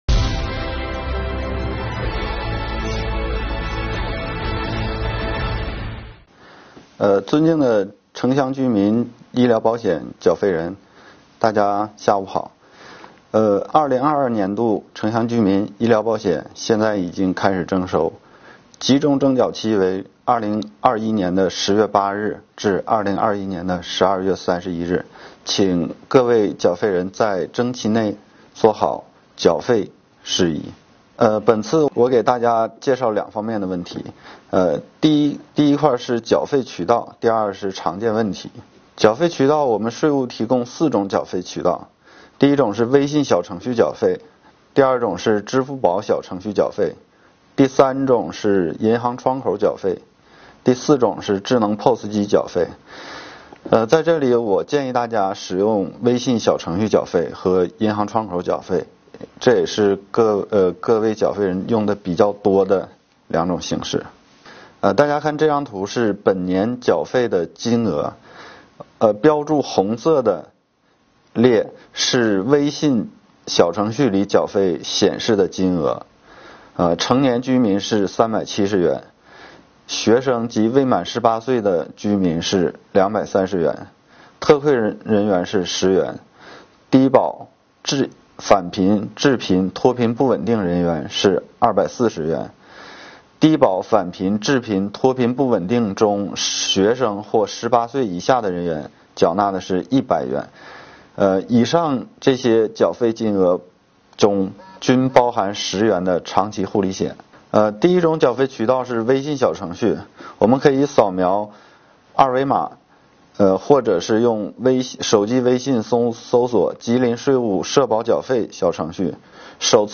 2021年第32期直播回放：长春市城乡居民基本医疗保险缴费政策及常见问题